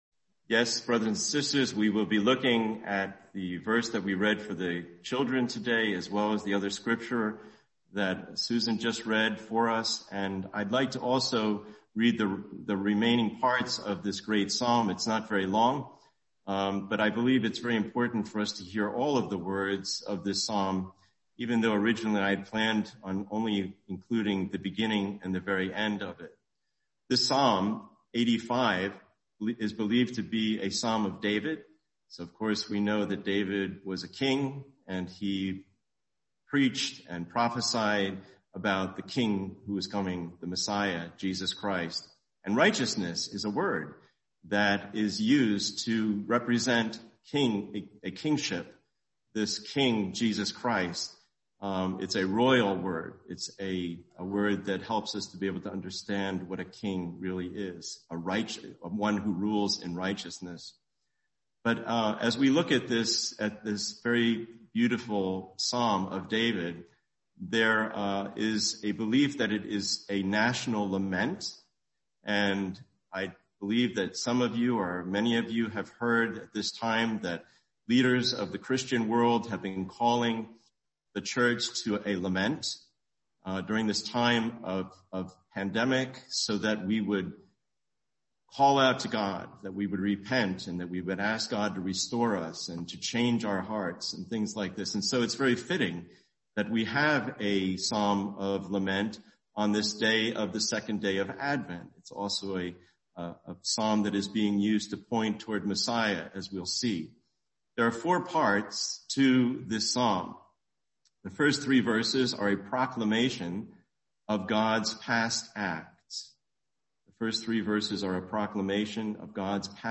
Video: Sunday English Worship Video